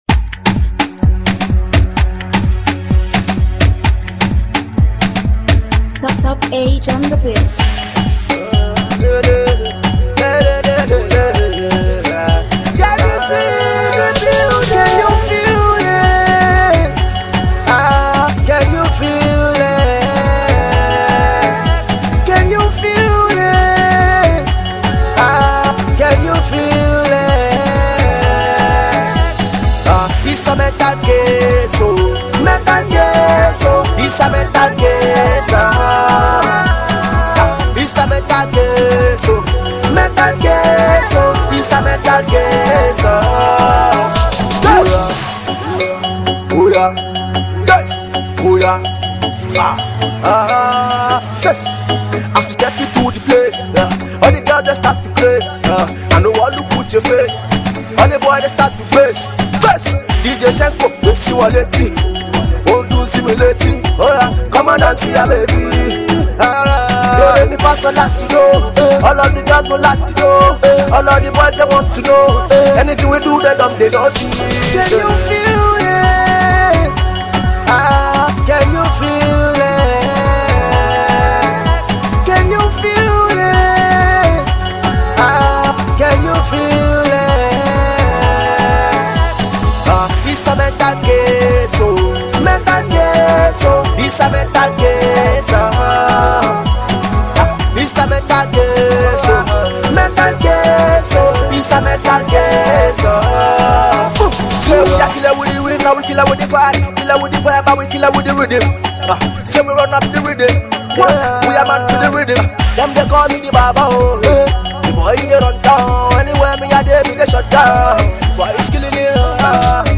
Street Pop
Its a Rowdy, Energetic pop single you can download below.